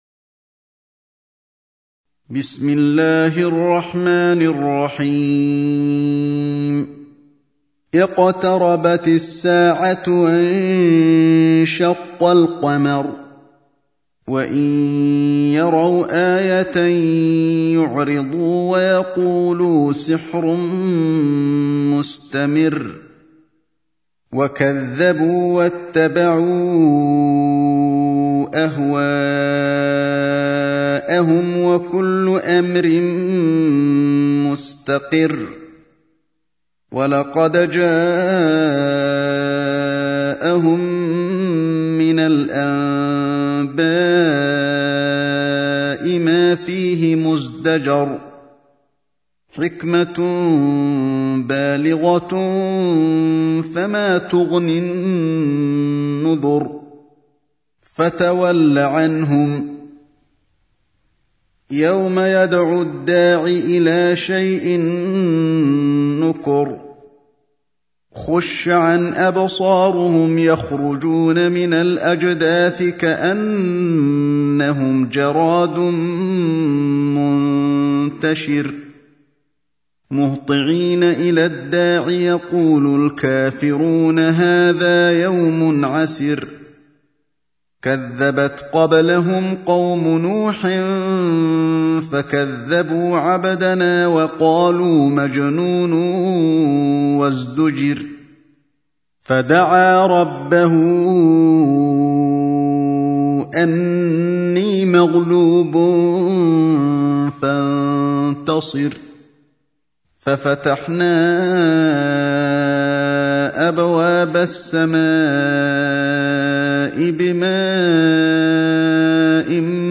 54. Surah Al-Qamar سورة القمر Audio Quran Tarteel Recitation Home Of Sheikh Ali Alhuthaifi :: الشيخ علي الحذيفي
Surah Repeating تكرار السورة Download Surah حمّل السورة Reciting Murattalah Audio for 54.